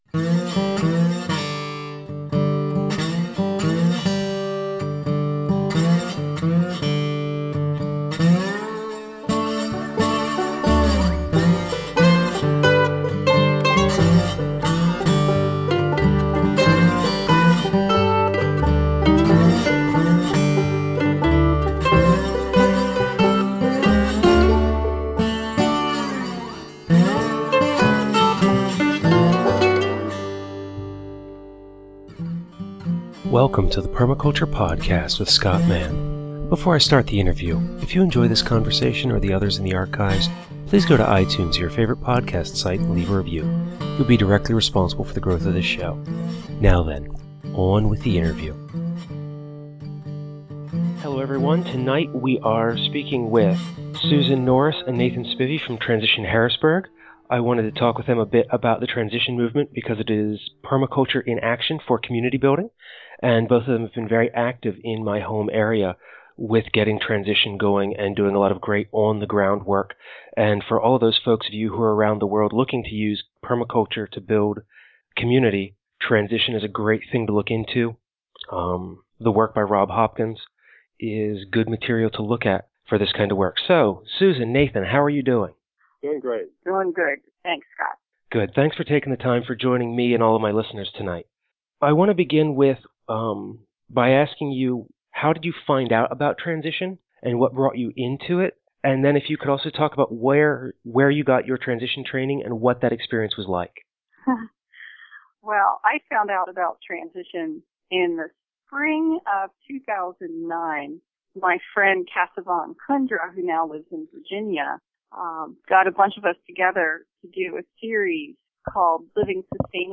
Tags interview